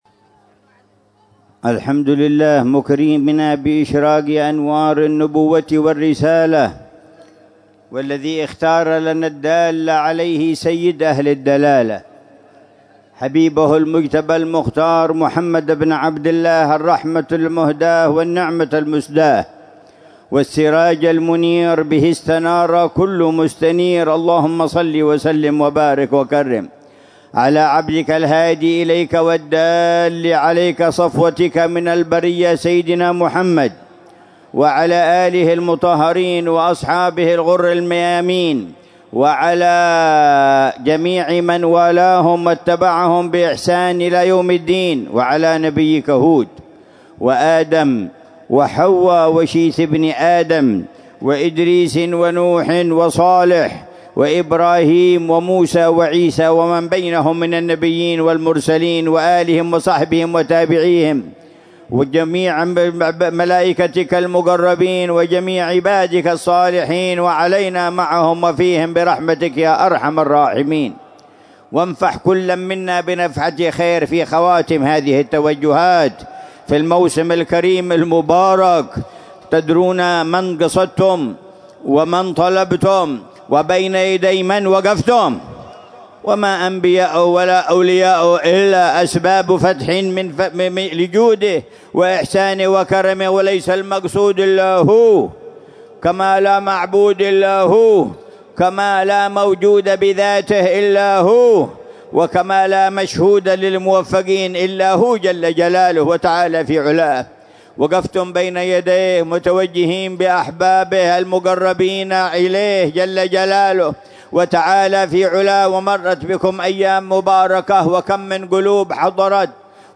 مذاكرة العلامة الحبيب عمر بن محمد بن حفيظ في المولد السنوي في زيارة آل الشيخ أبي بكر للنبي هود عليه السلام، ليلة الإثنين 11 شعبان 1446هـ بعنوان: